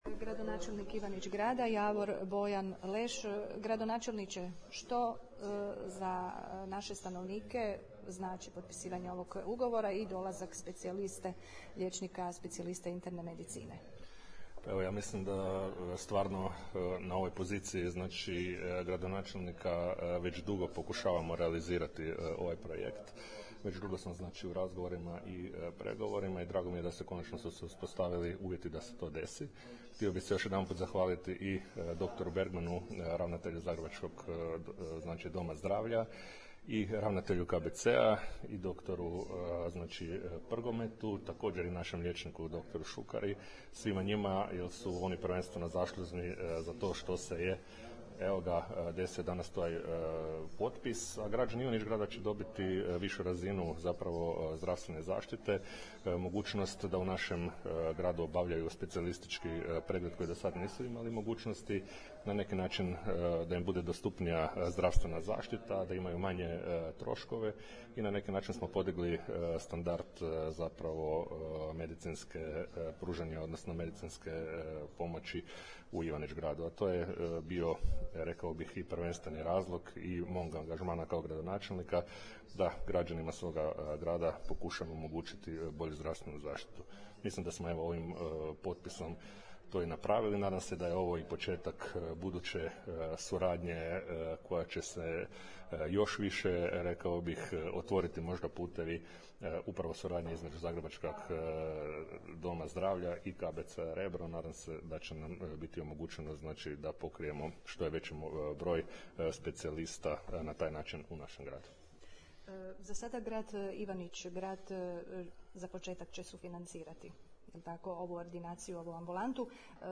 POSLUŠAJTE RAZGOVOR: gradonačelnik Javor Bojan Leš